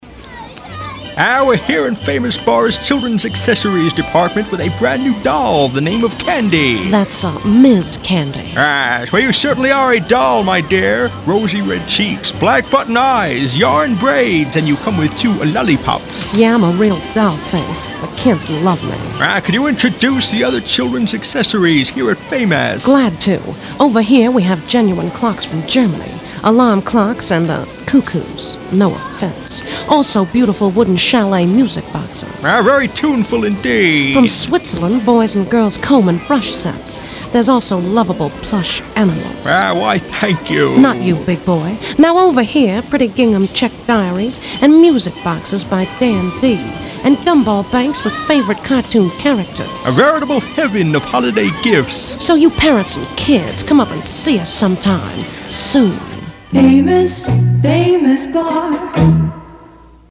In this children’s toy promotion for the huge St. Louis department store chain—one of my first efforts at the Byer and Bowman ad agency in Columbus—I cast myself as Fields.
It won First Prize at the National Retail Advertising Conference, as one of the best radio commercials in the country.